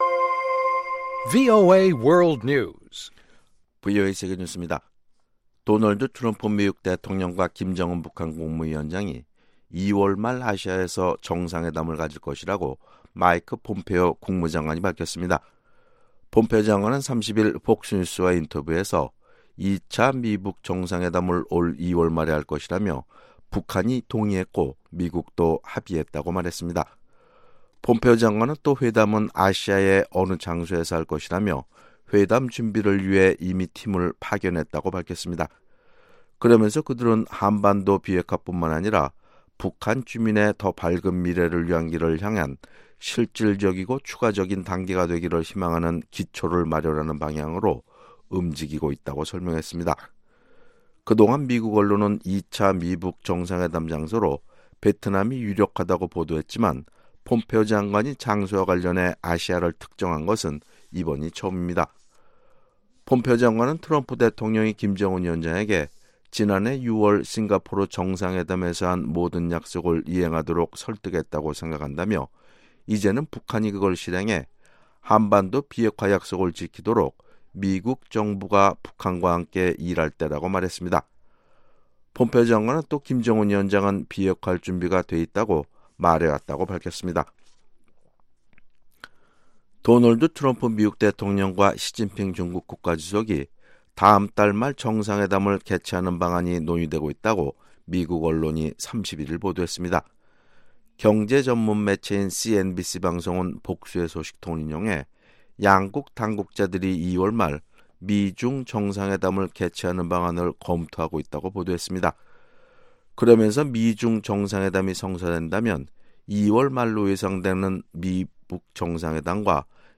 VOA 한국어 아침 뉴스 프로그램 '워싱턴 뉴스 광장' 2019년 2월 1일 방송입니다. 미국 하원에서 주한미군 감축 조건을 강화하는 법안이 발의됐습니다.